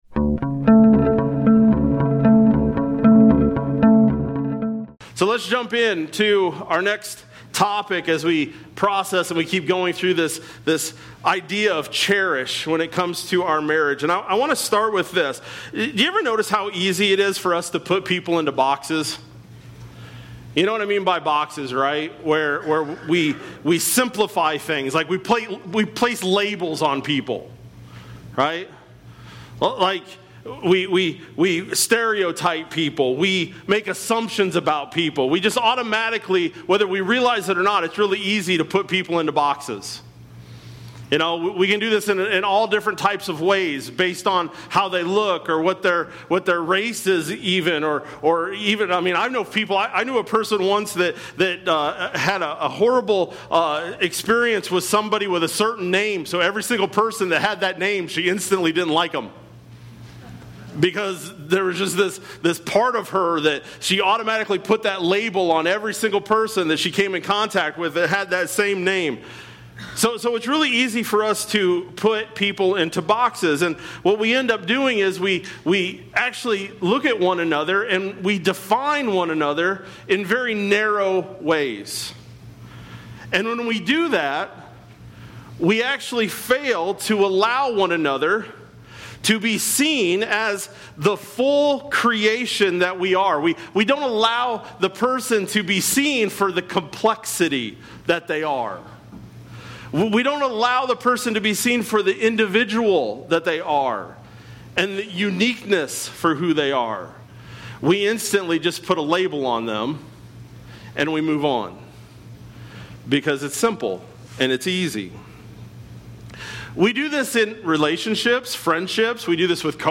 Sept-28-25-Sermon-Audio.mp3